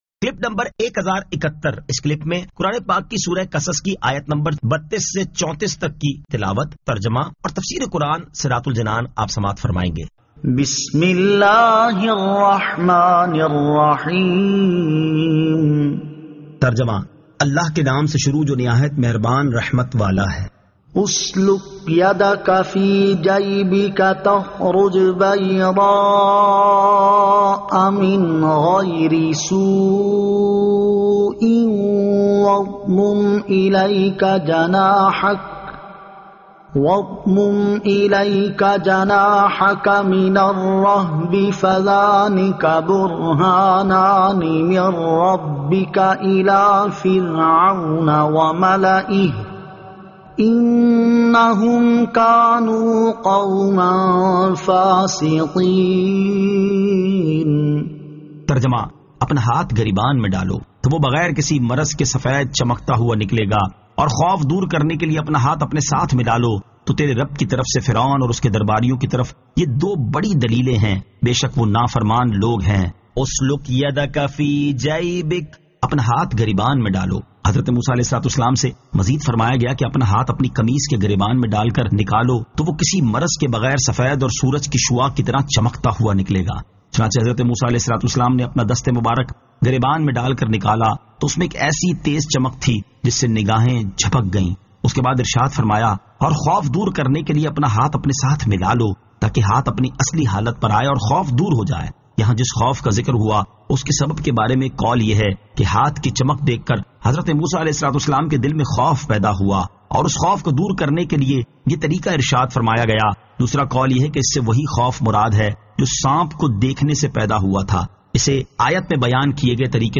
Surah Al-Qasas 32 To 34 Tilawat , Tarjama , Tafseer